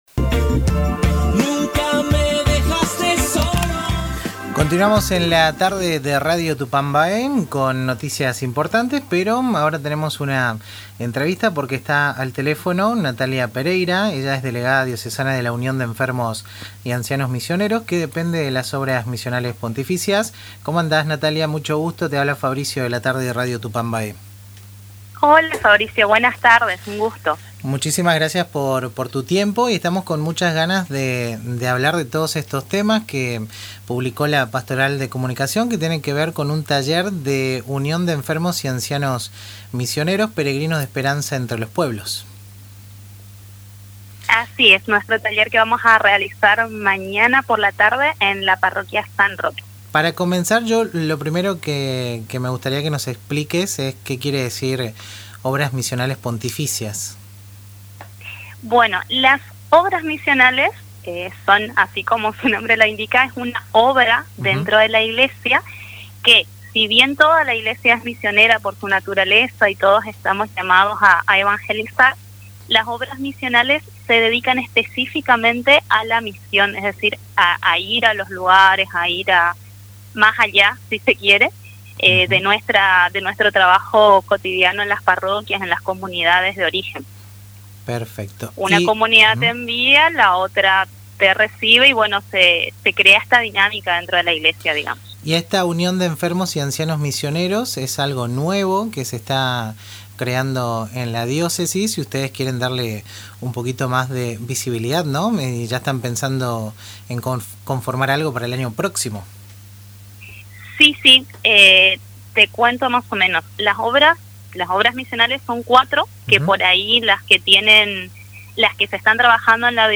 En diálogo con Radio Tupambaé